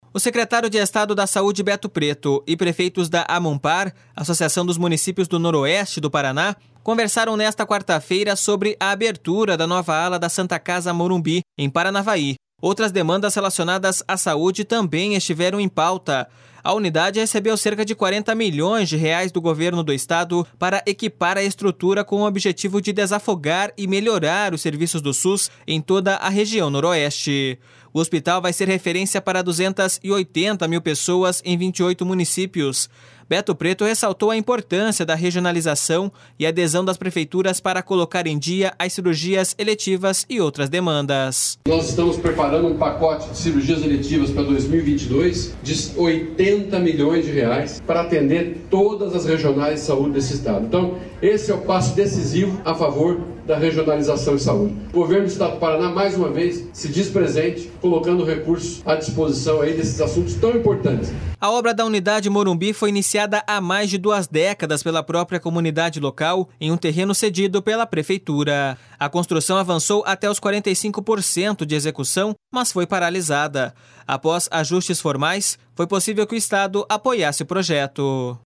Beto Preto ressaltou a importância da regionalização e adesão das prefeituras para colocar em dia as cirurgias eletivas e outras demandas. //SONORA BETO PRETO//